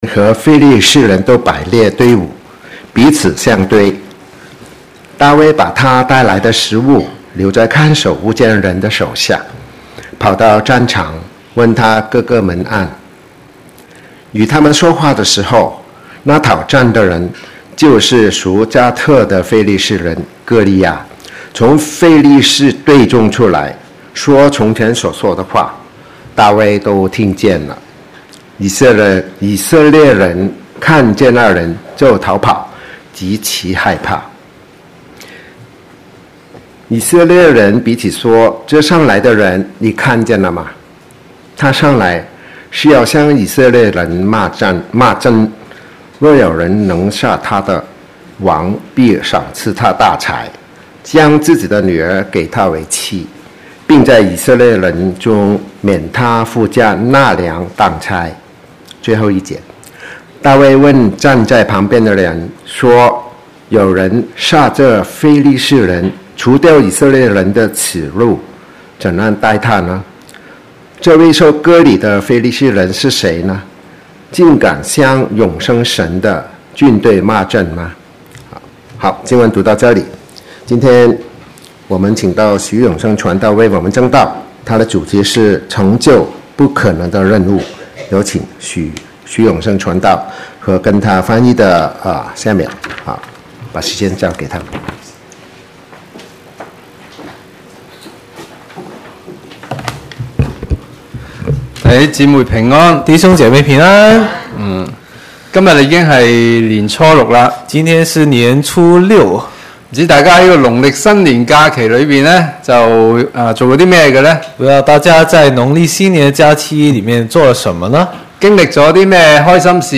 Posted in 主日崇拜